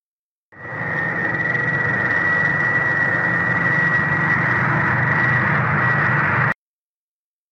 jet_GRFR31r.mp3